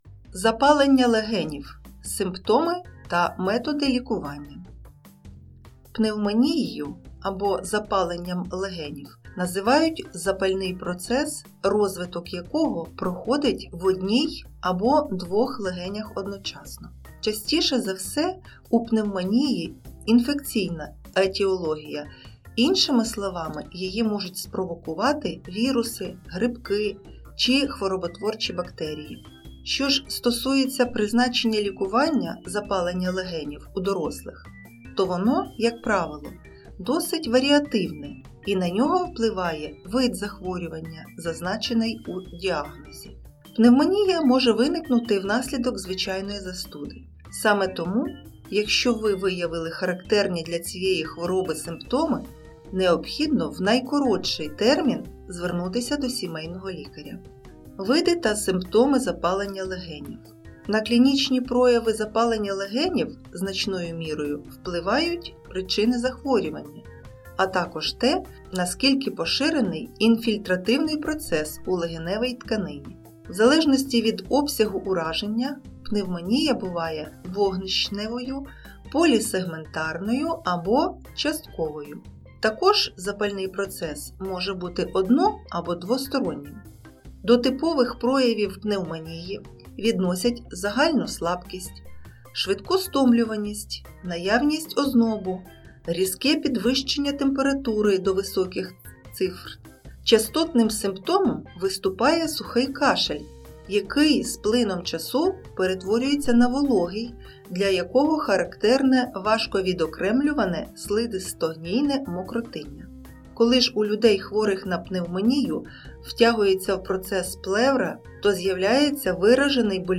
• Озвучив(ла)
Працівники бібліотеки